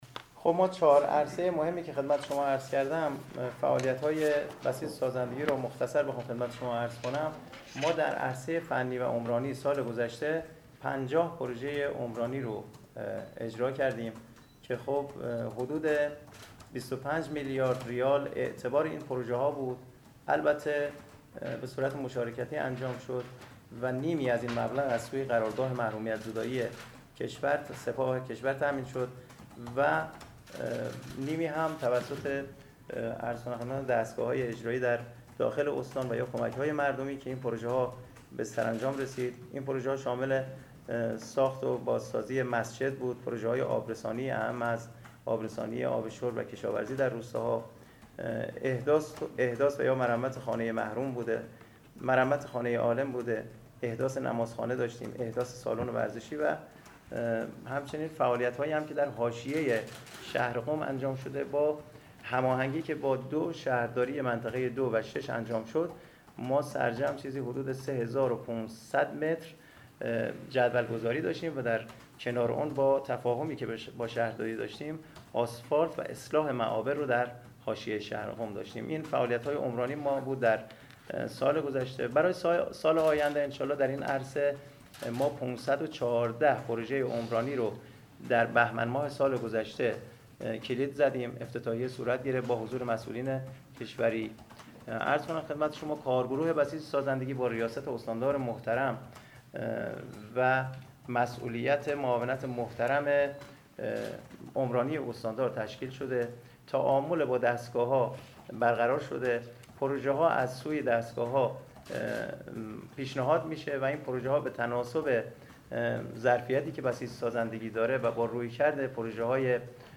در نشست خبری هفته بسیج سازندگی در دفتر خبرگزاری ایرنا در قم برگزار شد